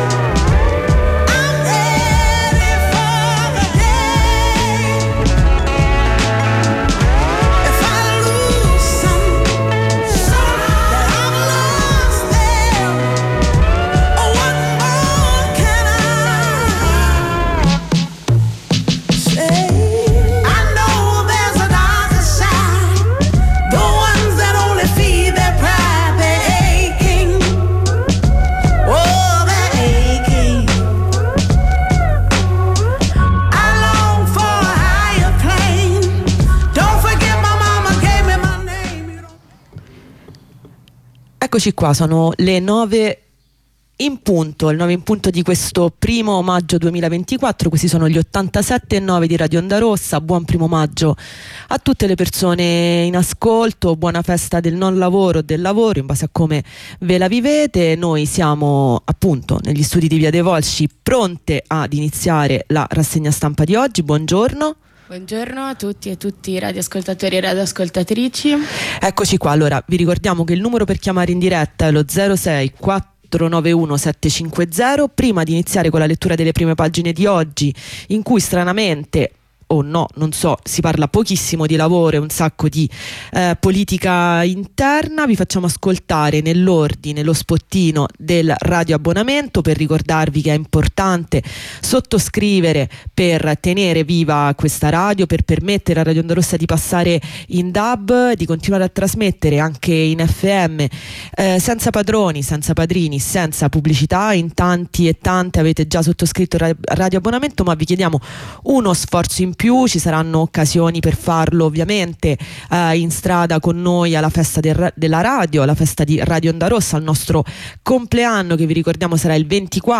Lettura delle prime pagine dei quotidiani nazionali e approfondimenti